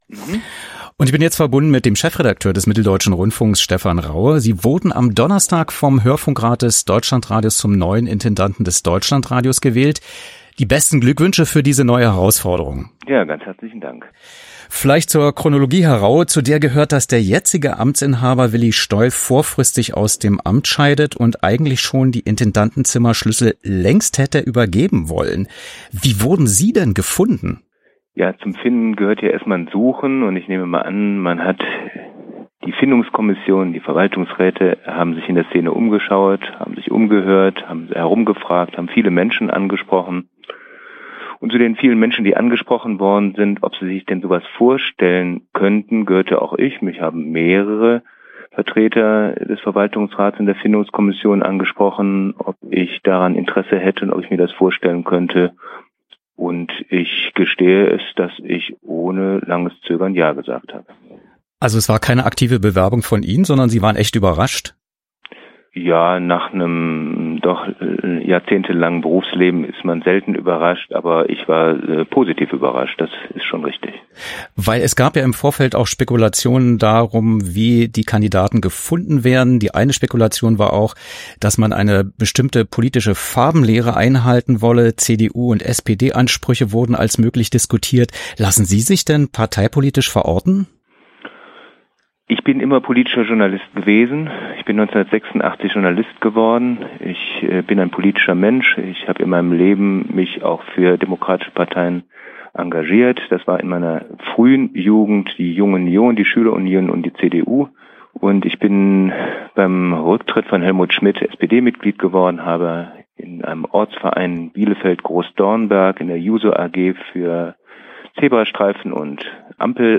Was: Interview über die Wahl zum Deutschlandradio-Intendanten am 08.06.2017